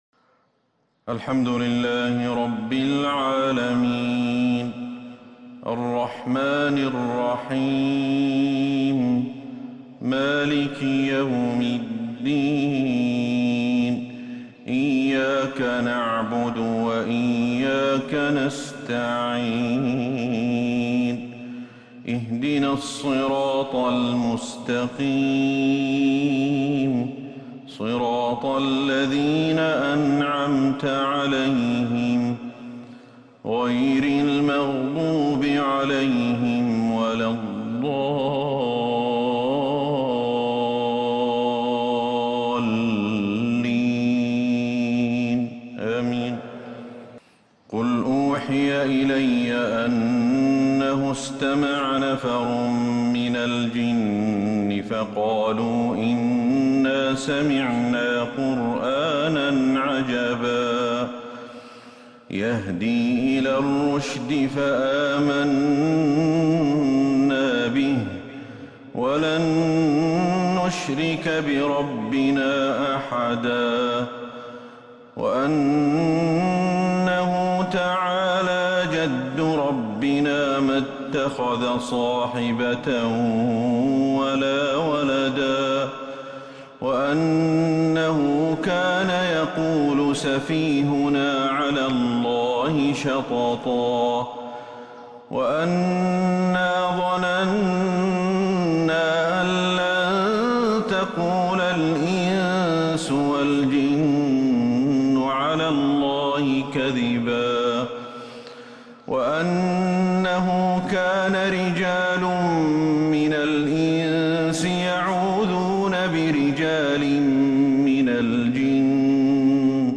فجر 1-8-1442هـ تلاوهـ بديعة لسورة الجن > 1442 هـ > الفروض - تلاوات الشيخ أحمد الحذيفي